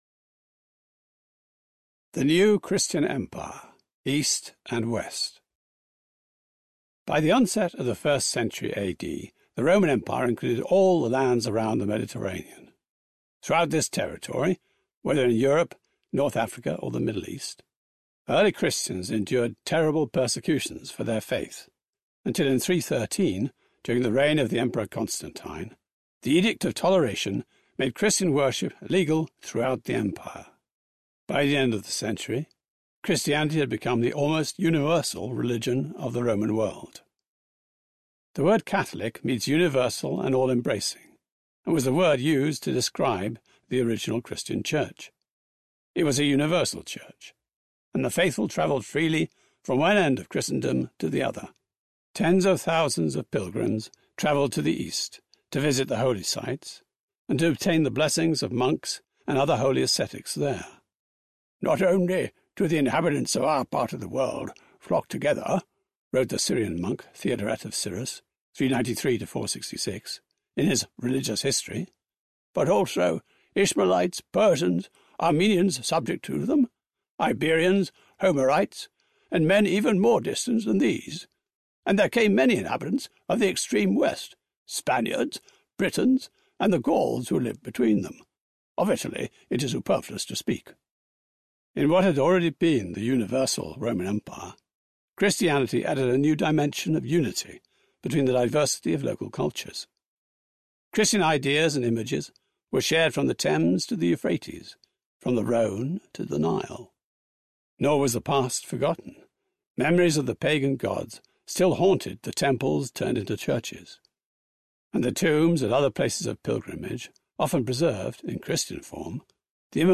The Templars: The History and the Myth Audiobook
11.0 Hrs. – Unabridged